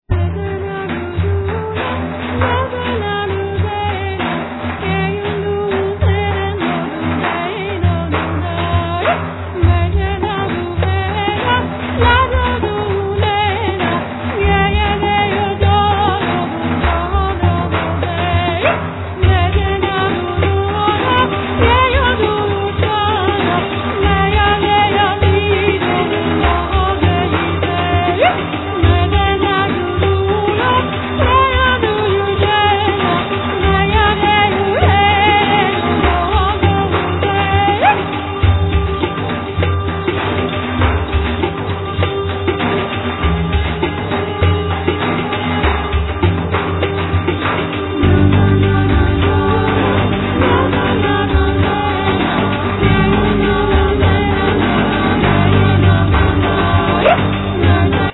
Vocals, Percussions
Programming, Guitars, Saz, Percussions
Programming, Keyboards, Santur, Percussions
Percussions, Tapan, Gaidunitza, Daf
Renaissance lute, Block flute